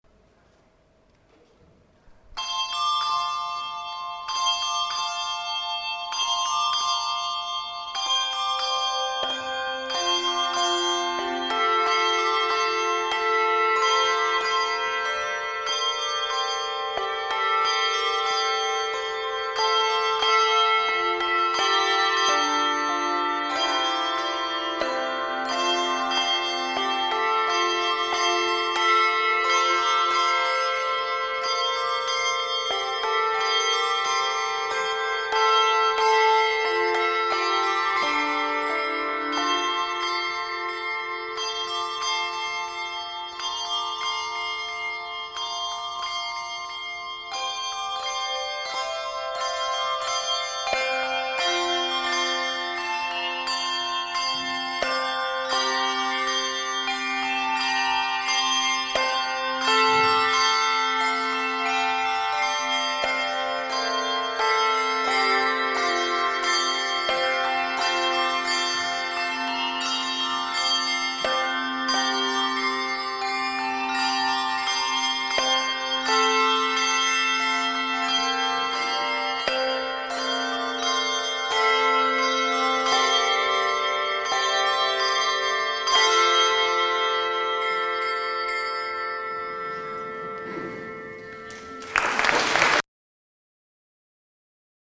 Handbells
We are also most fortunate to have an extensive library of handbell arrangements, all especially composed for the choir and the vibrant acoustic of the Chapter House of York Minster.
His handbell arrangements were commissioned in recent years and make full use of all the bells.
played at the Carols by Candlelight in 2016